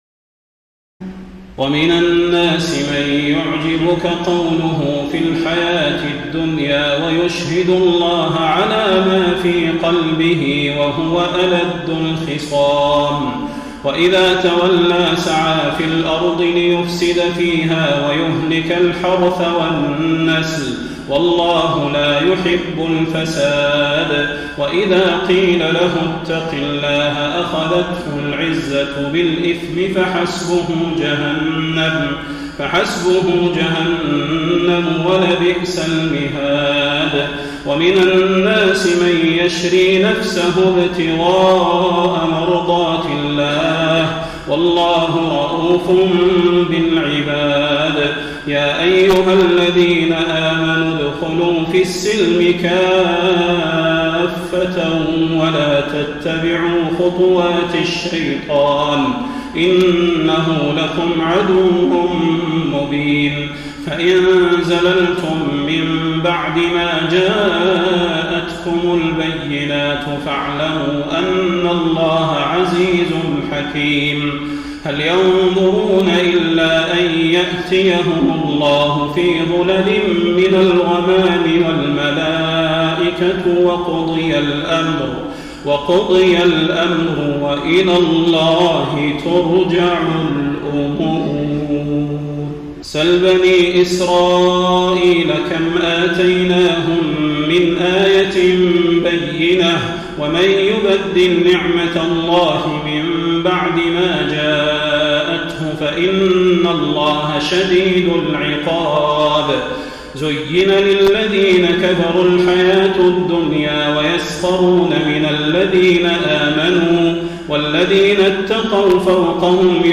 تراويح الليلة الثانية رمضان 1433هـ من سورة البقرة (204-252) Taraweeh 2 st night Ramadan 1433H from Surah Al-Baqara > تراويح الحرم النبوي عام 1433 🕌 > التراويح - تلاوات الحرمين